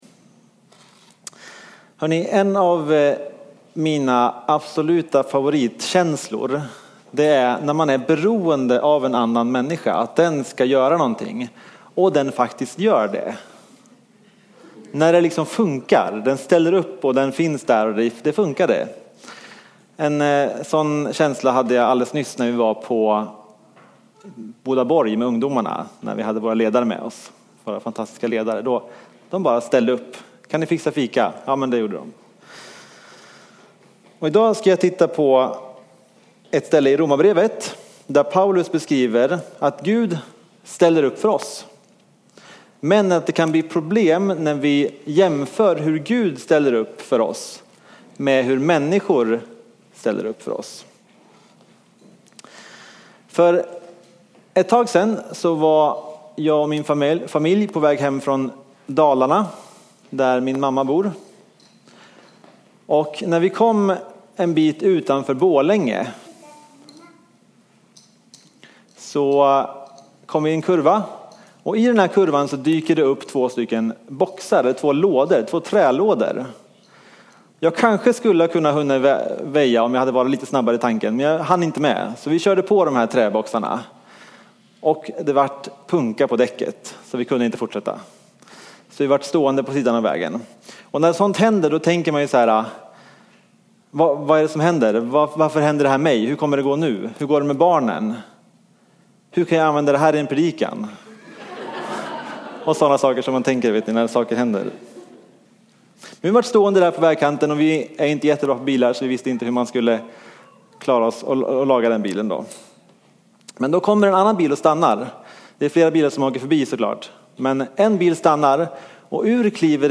A predikan from the tema "Fristående HT 2017."